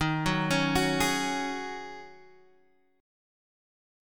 D#m11 chord